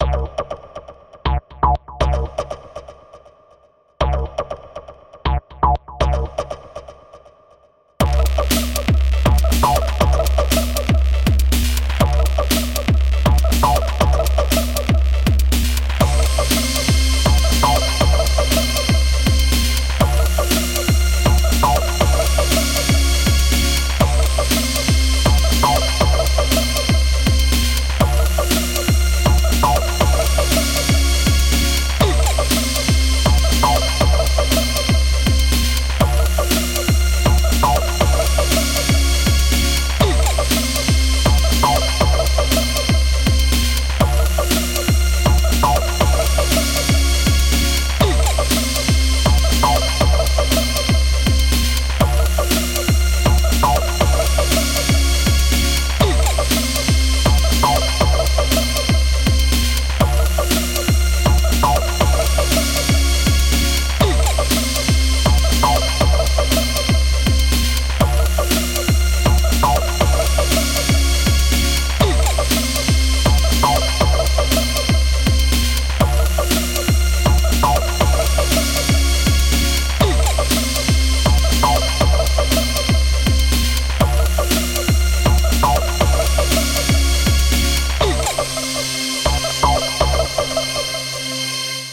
Pack from 1 to 5 [LOW quality].